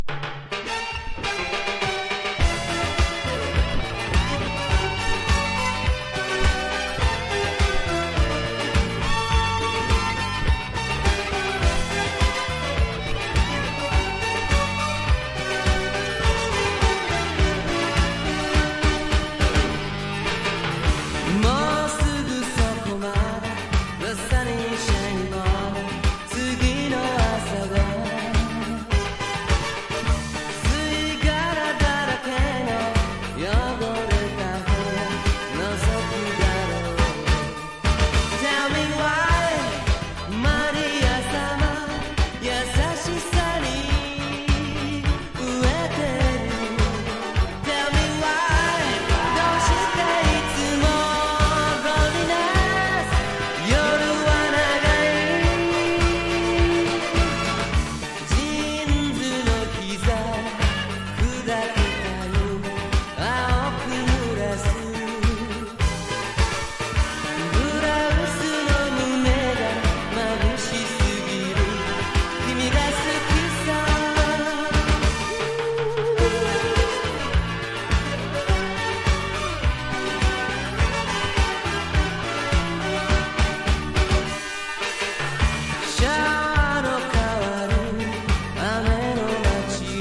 SSW / FOLK# CITY POP / AOR# 和モノ